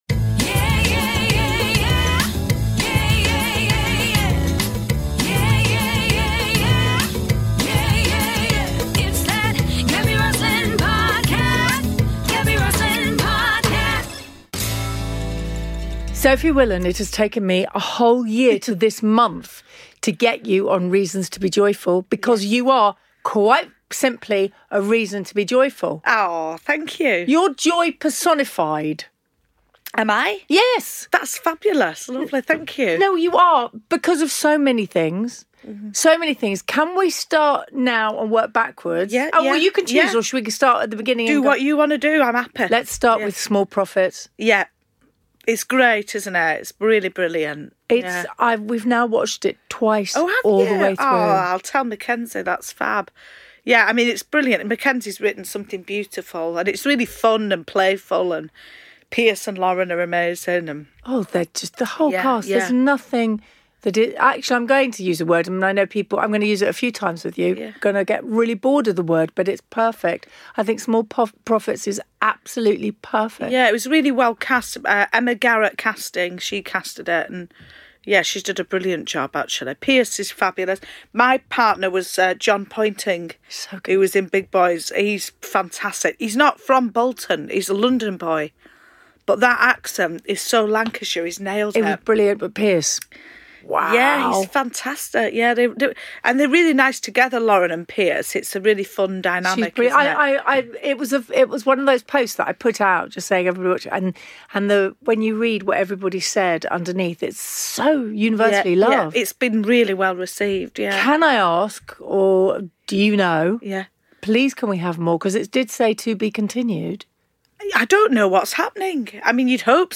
Each week, she welcomes a famous face to the studio to find out what makes them smile, chuckle and belly laugh.